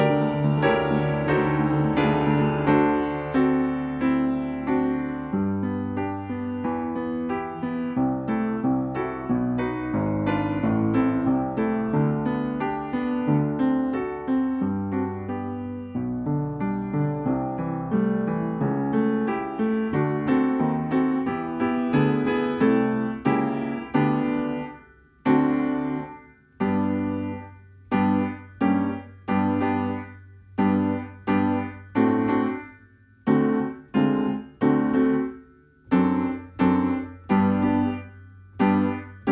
II wersja: 90 BMP
Nagrania dokonane na pianinie Yamaha P2, strój 440Hz
piano